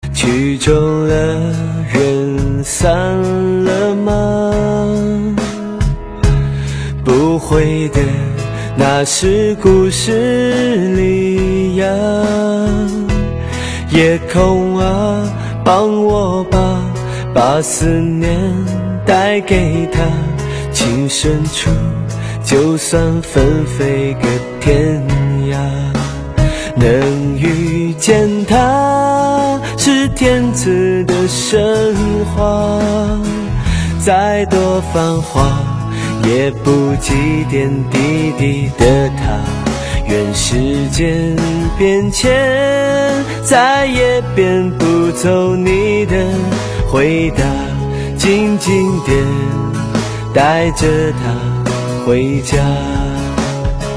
M4R铃声, MP3铃声, 华语歌曲 46 首发日期：2018-05-15 20:41 星期二